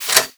m3_spin.wav